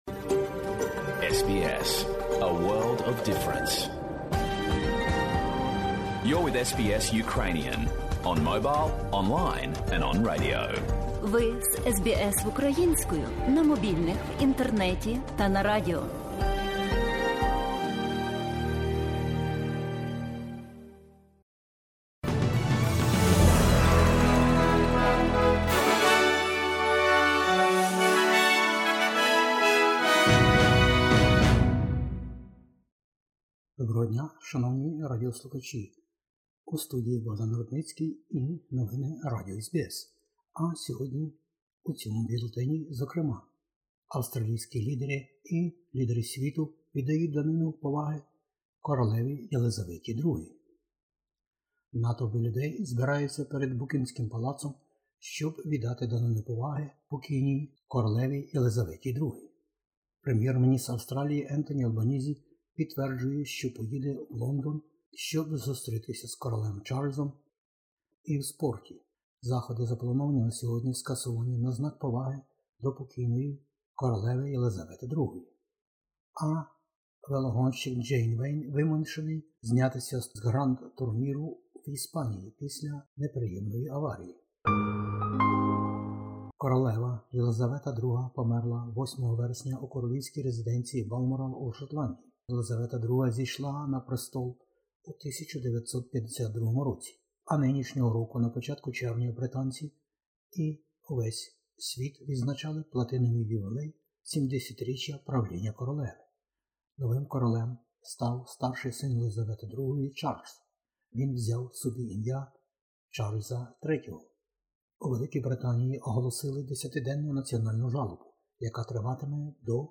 Бюлетень SBS новин - 9/09/2022